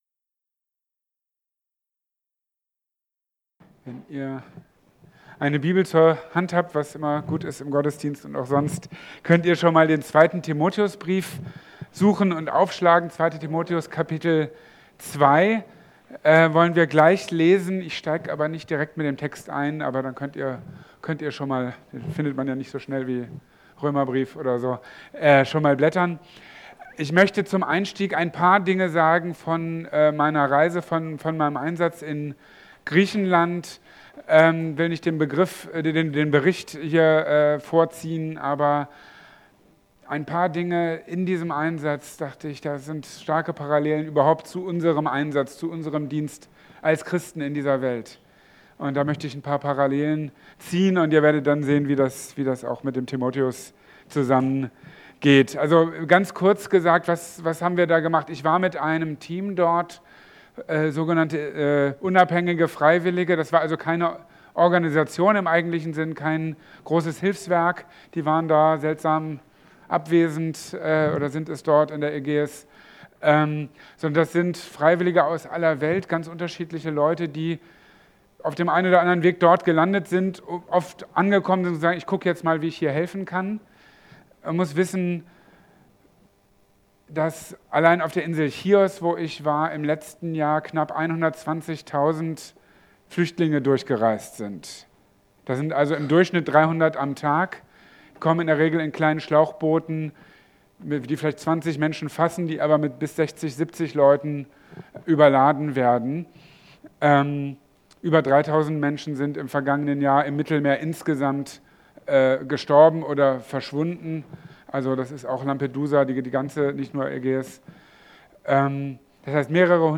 In seiner Predigt vom 17.